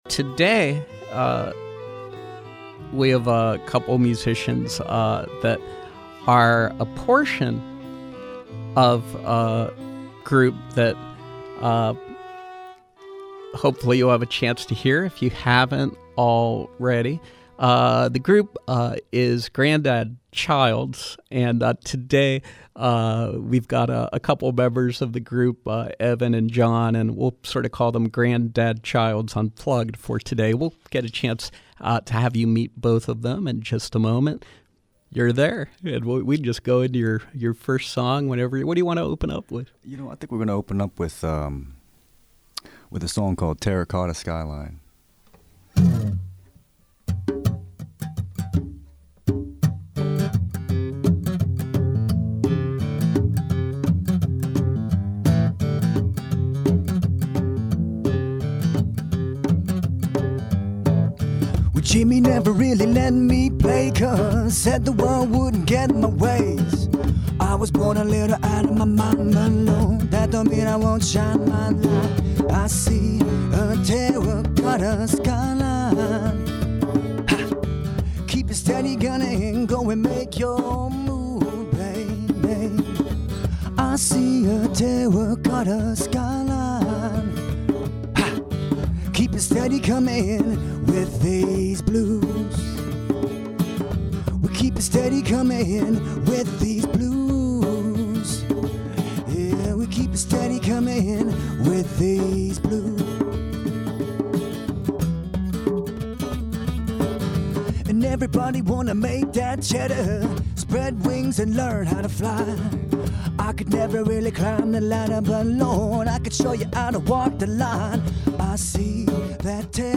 acoustic originals